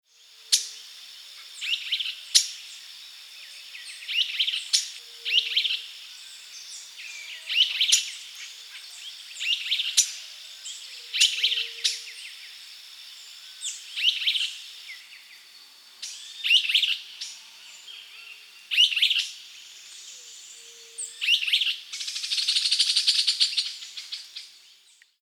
Nome Português: Bem-te-vi-rajado
Nome em Inglês: Streaked Flycatcher
Fase da vida: Adulto
Localidade ou área protegida: Delta del Paraná
Condição: Selvagem
Certeza: Observado, Gravado Vocal